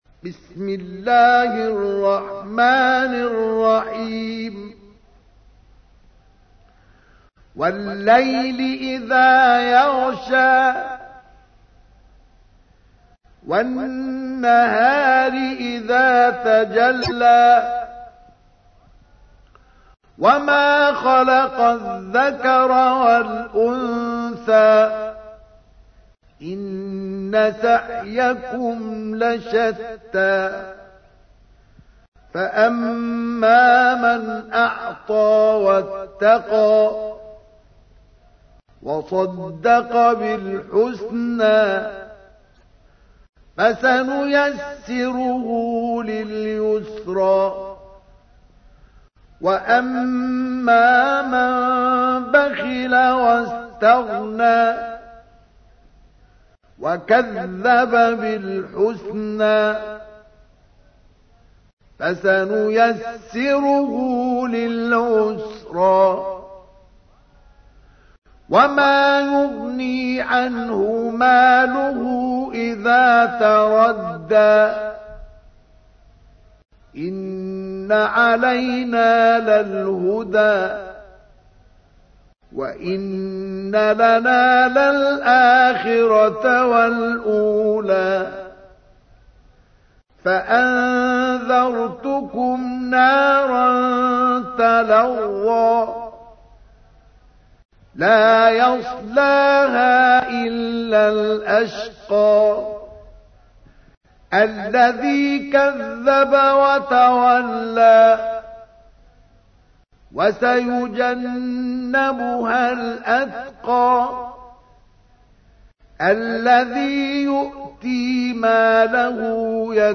تحميل : 92. سورة الليل / القارئ مصطفى اسماعيل / القرآن الكريم / موقع يا حسين